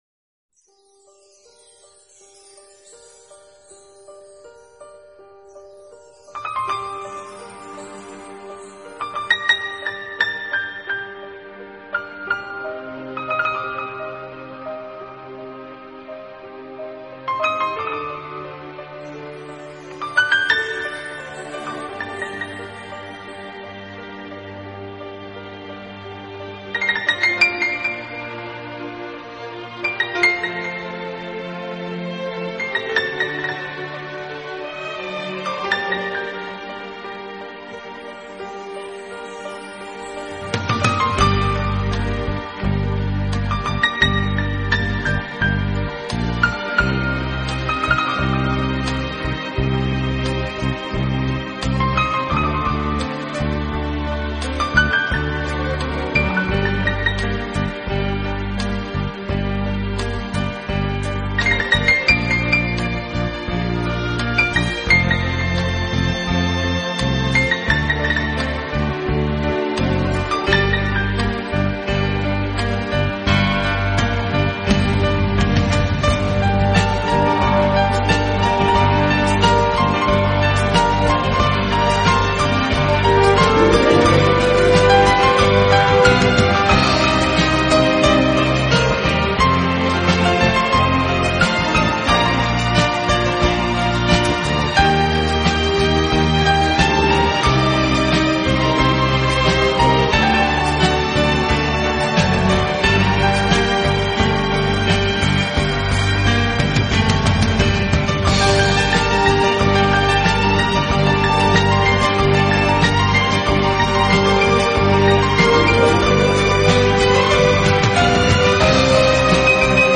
专辑语言：纯音乐
NEW AGE音乐作曲家和钢琴家。